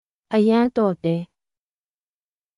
アヤン　トーデー
当記事で使用された音声（日本語およびミャンマー語）はGoogle翻訳　および　Microsoft Translatorから引用しております。